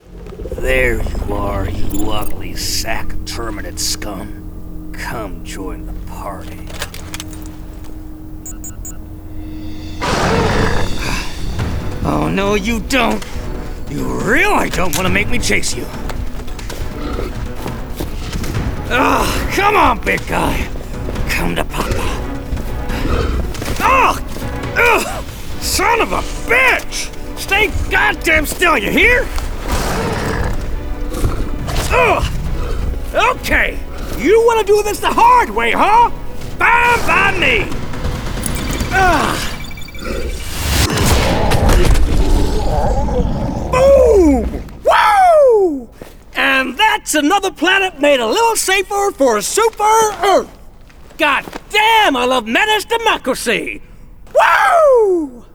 US Accent Showreel
Male
1-general-american-accent-reel.wav